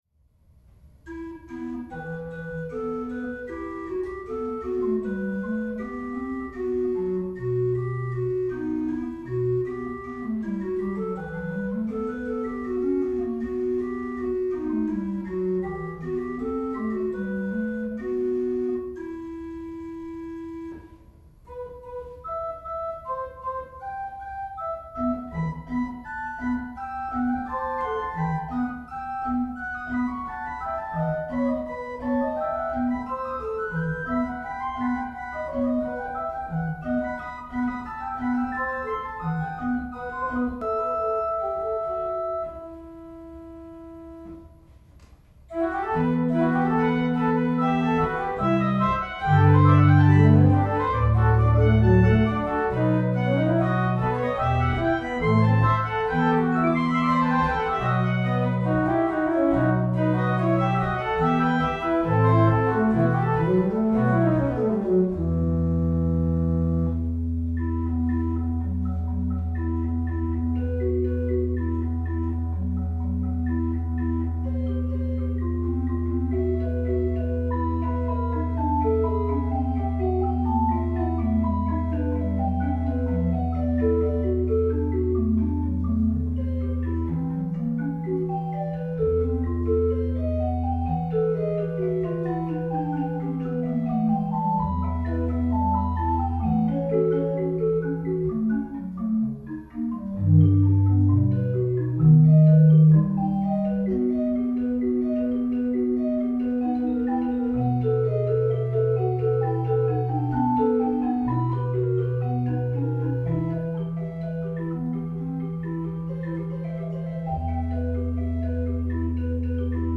RECORDED ON HIS RESIDENCE ORGAN
Registrations:
m. 71: I: 8, 4, 1 1/3
m. 100: I: 8; II: 8 (2 8ves higher)
Rohrflöte 8’
Principal 4’
Quintflöte 1 1/3'
Gedackt 8’
Mechanical key and stop action